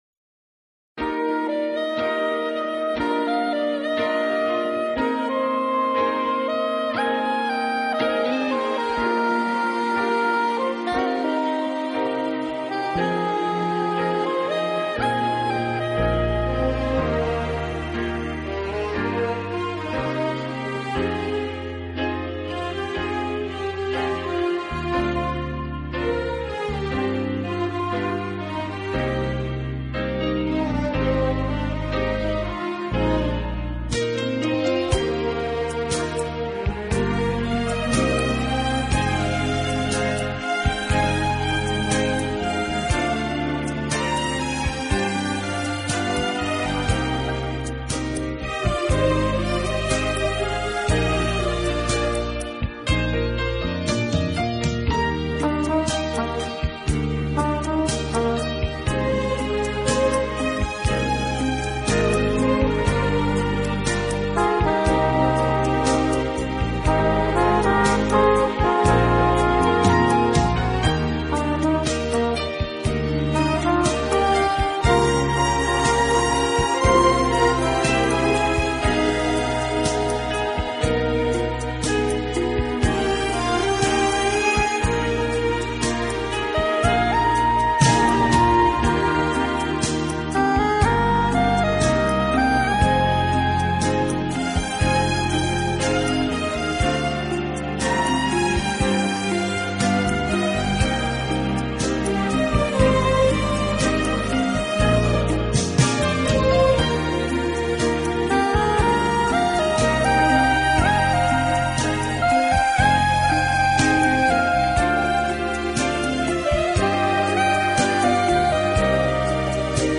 这是一组来自音乐家心灵深处，表露世界各地独特浪漫风情的音乐。
十二集超过二百首流行音乐元素与世界各地风情韵味完美结合的音乐，